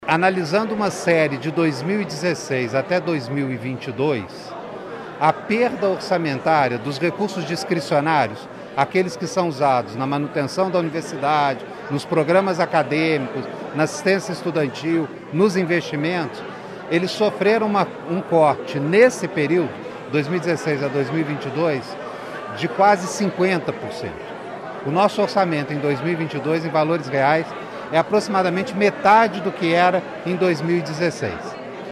Marcus David, reitor da UFJF